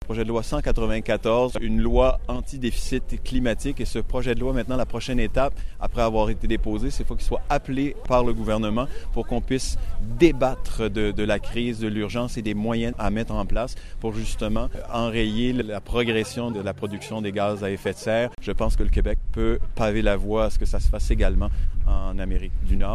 Le député Arseneau souligne que les politiciens sont à l’écoute de la société civile et que son parti a d’ailleurs présenté un projet de Loi à l’Assemblée nationale pour resserrer les objectifs de réduction de GES au Québec :